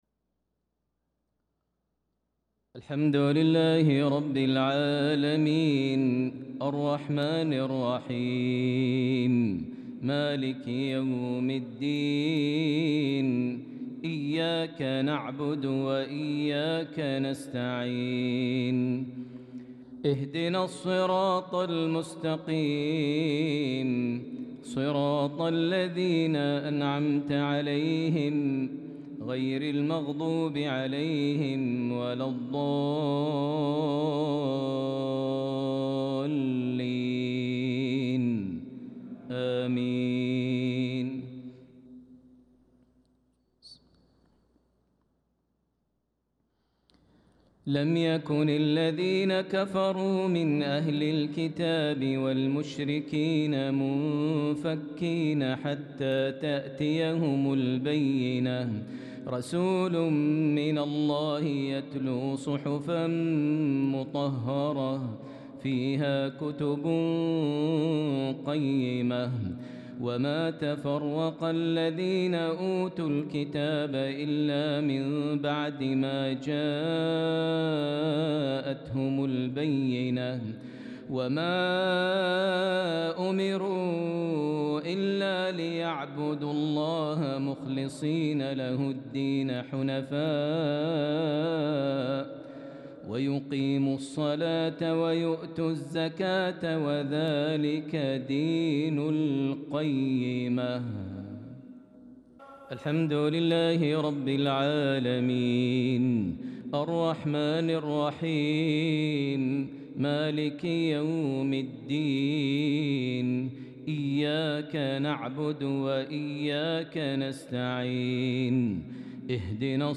صلاة المغرب للقارئ ماهر المعيقلي 27 ذو الحجة 1445 هـ
تِلَاوَات الْحَرَمَيْن .